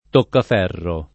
toccaferro [ tokkaf $ rro ] s. m.